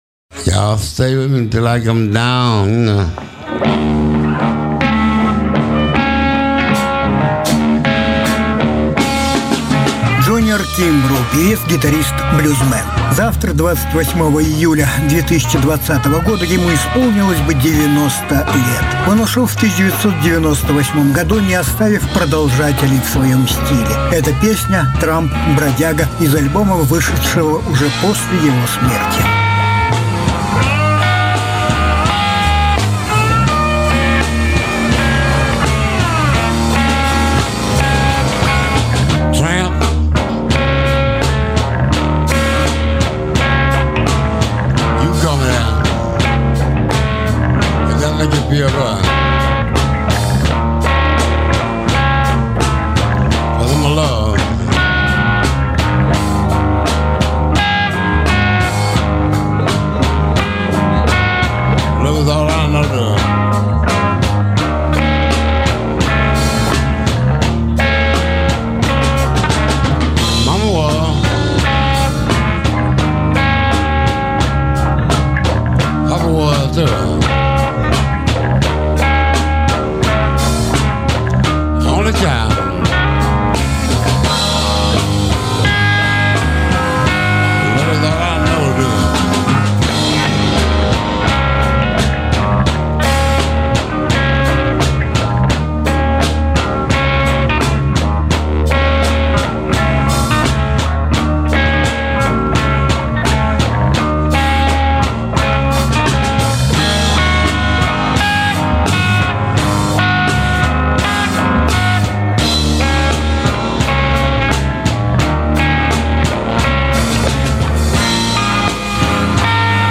Жанр: Блюзы